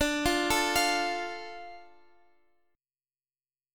Dm Chord (page 3)
Listen to Dm strummed